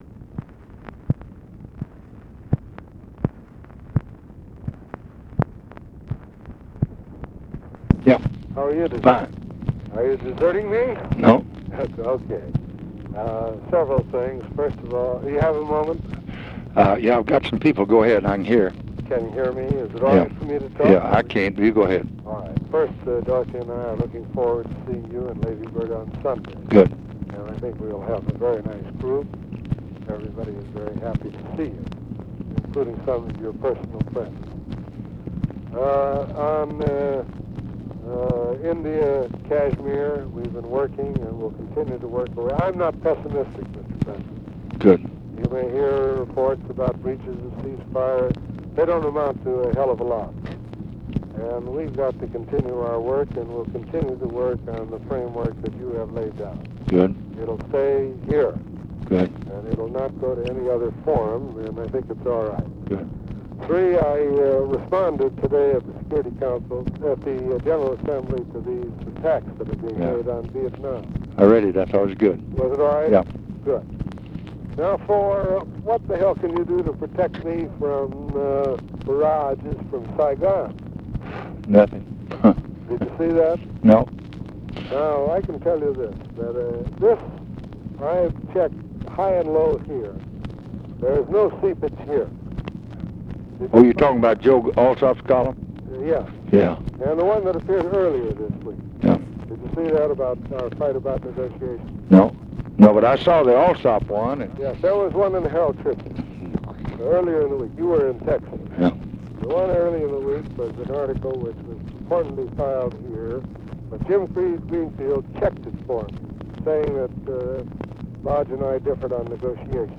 Conversation with ARTHUR GOLDBERG, October 1, 1965
Secret White House Tapes